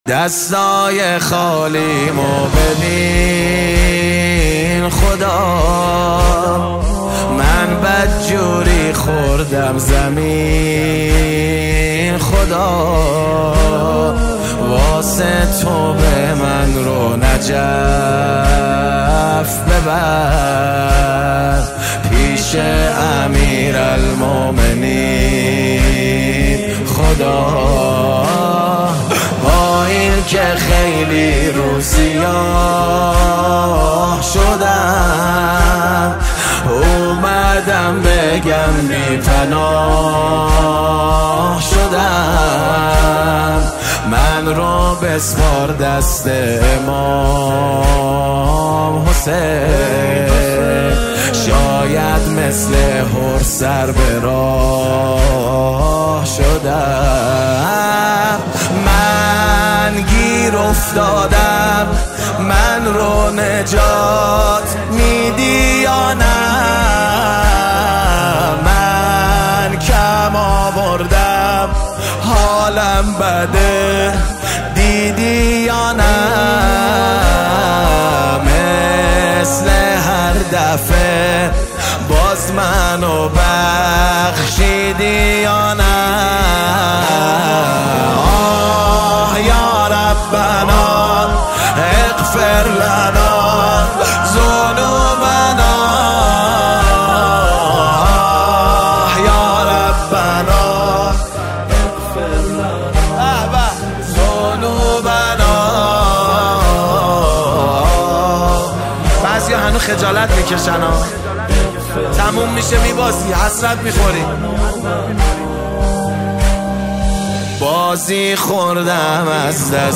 اثری دل‌انگیز و معنوی برای ماه مبارک رمضان است.